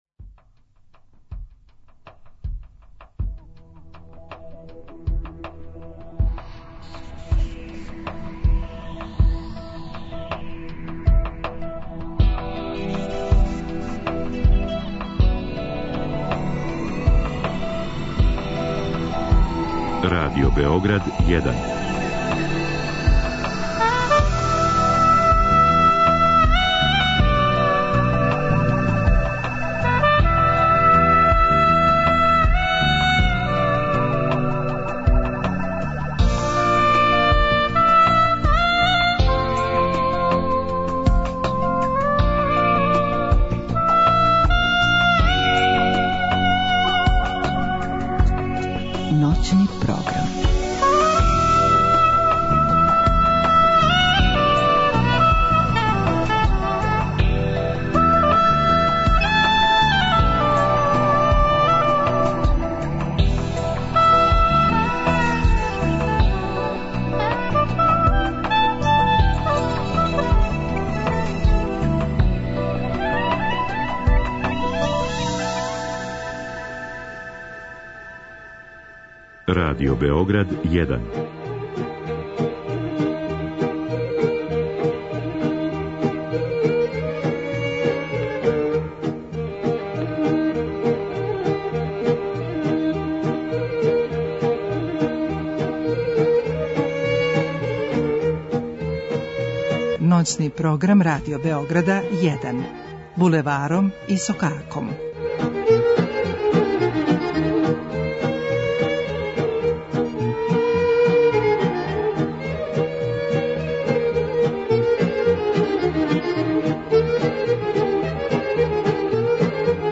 Будите уз Радио Београд 1 и моћи ћете да уживате уз праву традиционалну народну музику у најбољим стилизацијама и извођењима.